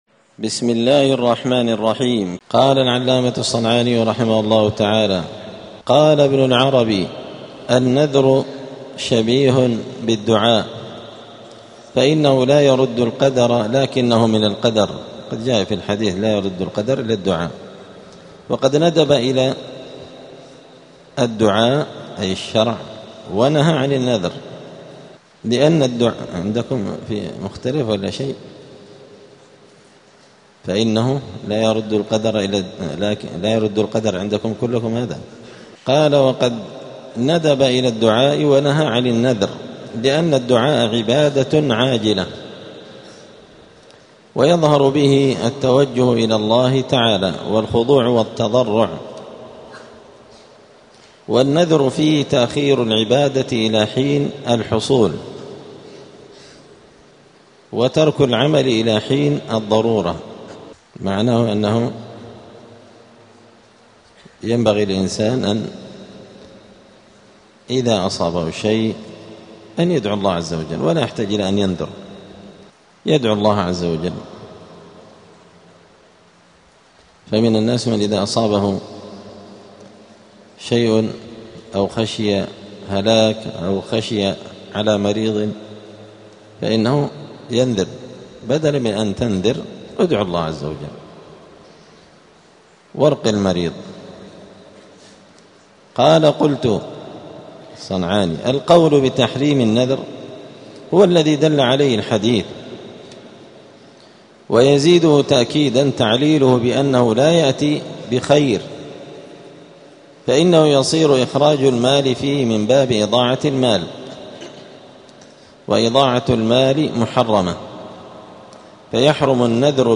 *الدرس الثالث عشر (13) {كفارة النذر}*